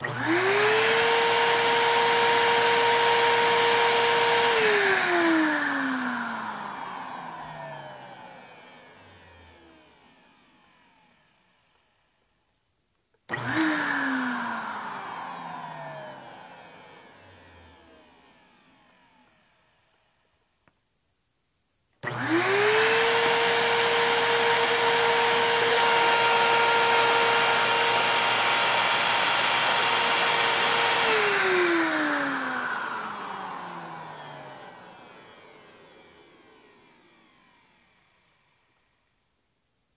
描述：记录了我的家用吸尘器的操作过程，包括打开和关闭几次。
Tag: 器具 家庭 真空清洁